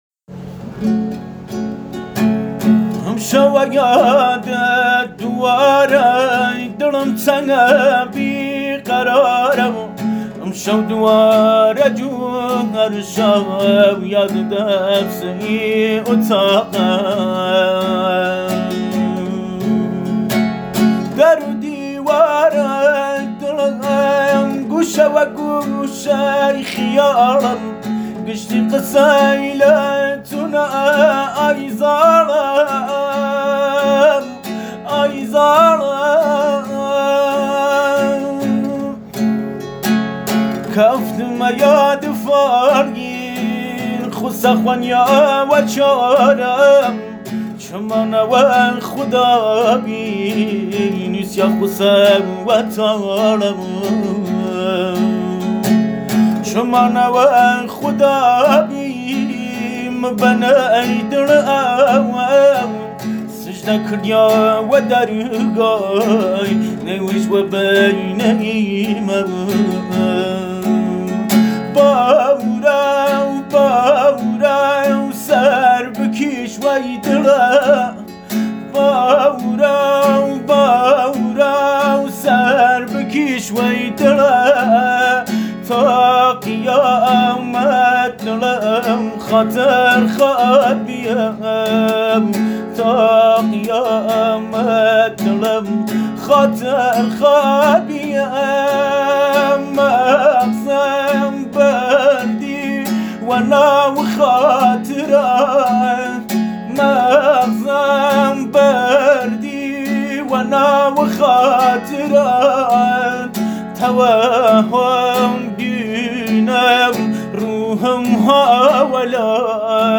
کردی غمگین با گیتار